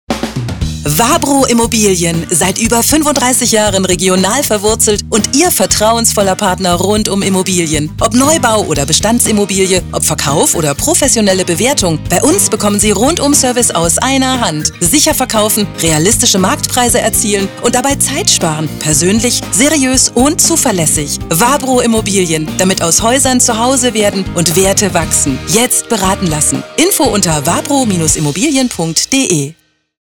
WABRO IMMOBILIEN IM RADIO- UNSER EXCLUSIVER RADIO-SPOT